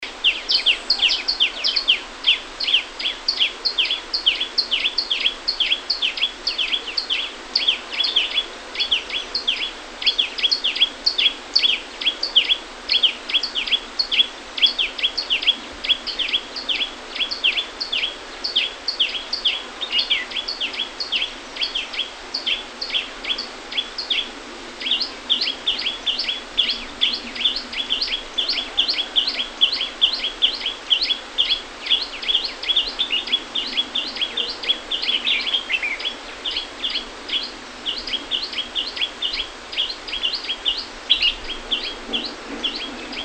Couturière à longue queue ( Orthomus sutorius ) Ssp longicauda
Cris d’alarme enregistrés le 07 janvier 2012, en Chine, province du Guangdong, à Wu Tong Shan près de la ville de Shenzhen.